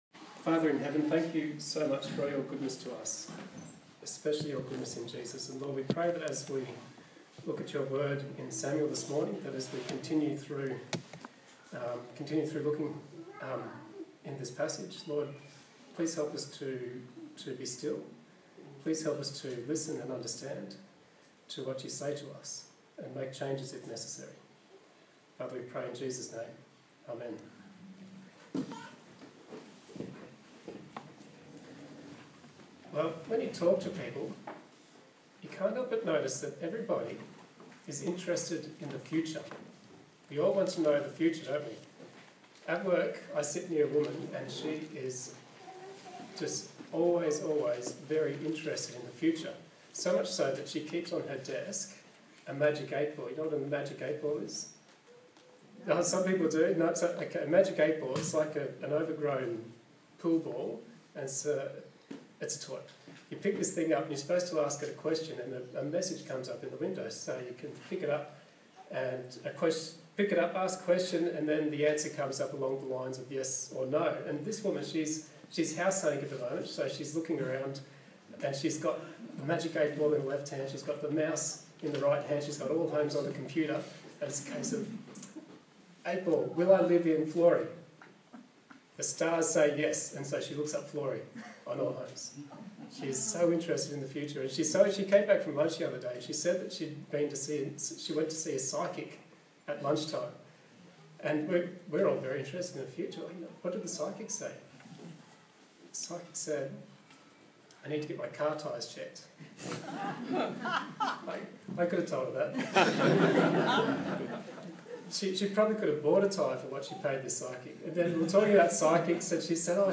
1 Samuel Passage: 1 Samuel 20 Service Type: Sunday Morning